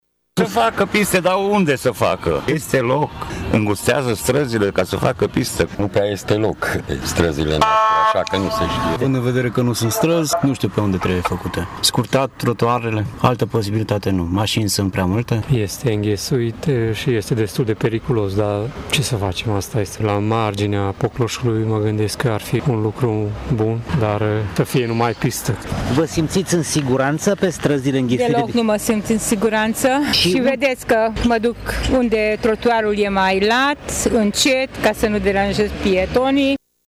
Tîrgumureșenii spun că pistele de biciclete sunt necesare, iar bicicliștii nu se simt tocmai în siguranță pe șoselele din Tîrgu-Mureș: